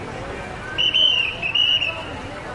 伦敦警察哨
标签： 警察哨 英国警察 吹口哨
声道立体声